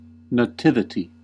Ääntäminen
Etsitylle sanalle löytyi useampi kirjoitusasu: nativity Nativity Ääntäminen US UK : IPA : /nəˈtɪvᵻti/ IPA : /nəˈtɪvəɾi/ Haettu sana löytyi näillä lähdekielillä: englanti Käännöksiä ei löytynyt valitulle kohdekielelle.